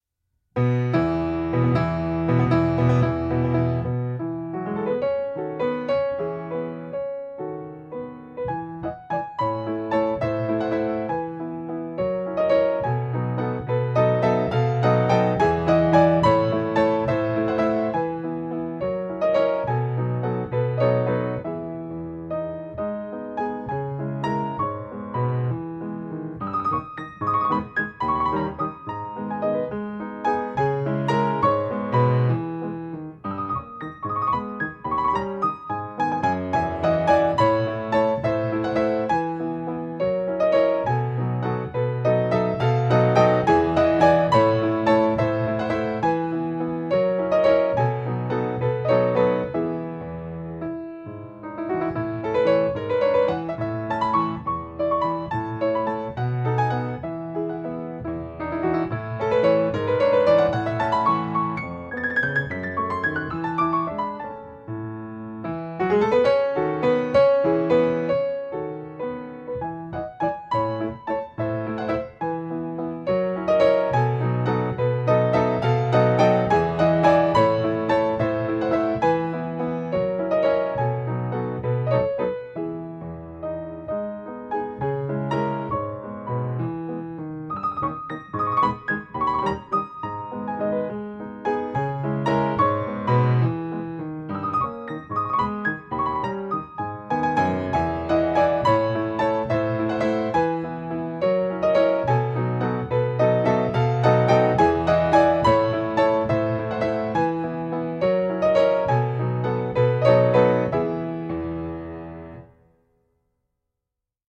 Piano  (View more Intermediate Piano Music)
Classical (View more Classical Piano Music)